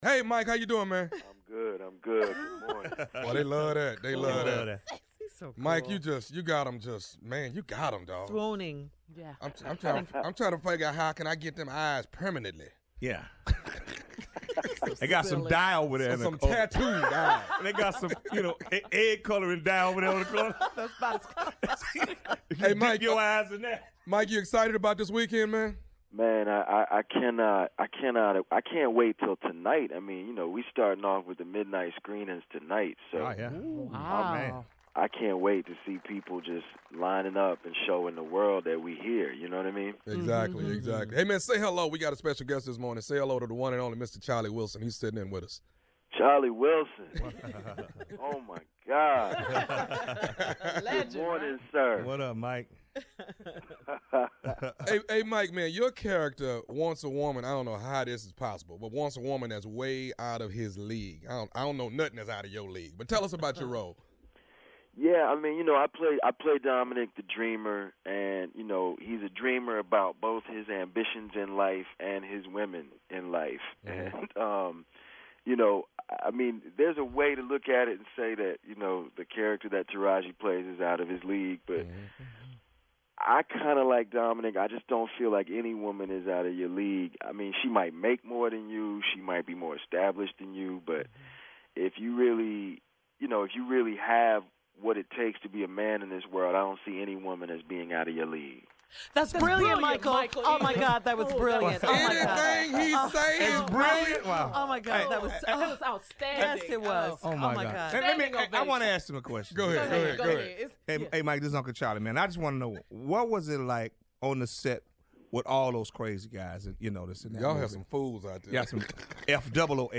Exclusive Interviews
Actor Michael Ealy called in to The Steve Harvey Morning Show to talk about his role in Steve Harvey’s Think Like A Man, which opens nationwide tomorrow (April 20).
With Charlie Wilson sitting in the studio, Michael discussed his character Dominic and how the role relates to his real life and relationships.